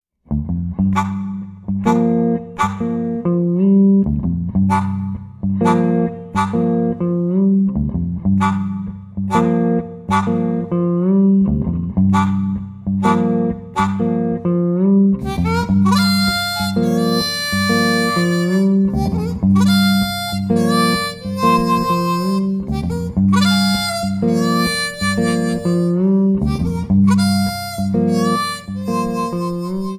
Lap Slide Steel Guitar
Harmonica
A lap slide guitar and a harmonica.
an instrumental blues duet